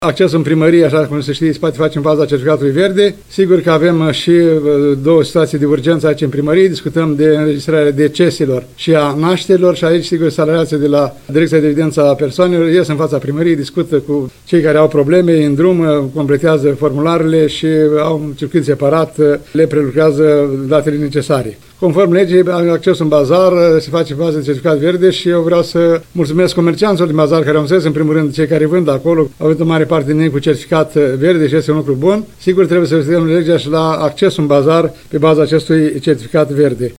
Primarul ION LUNGU a declarat astăzi că accesul în Primărie și în Bazar se face în baza certificatului verde COVID.